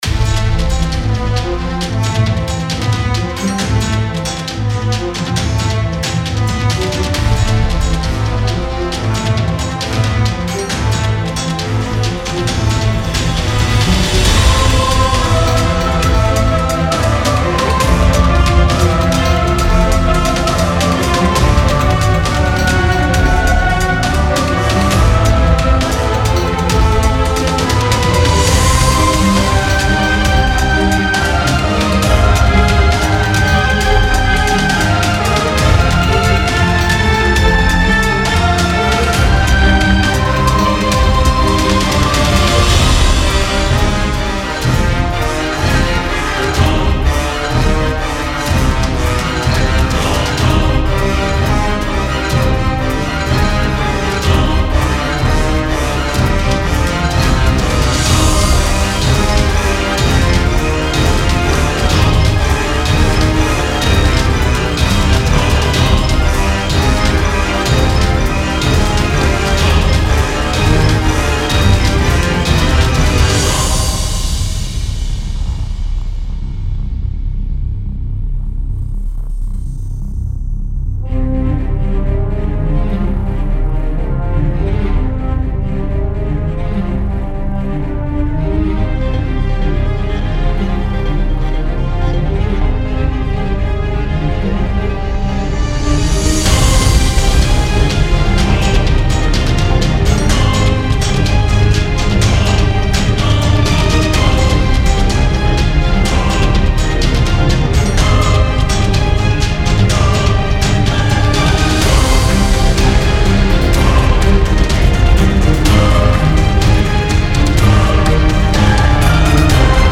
该包包括一组基于管弦乐器的WAV和MIDI文件以及诸如铃铛，铜管乐器，合唱团和声音效果之类的声音。
•125-135 BPM
•10个响铃循环
•20个黄铜环
•10个合唱循环
•20个鼓循环
•40个字符串循环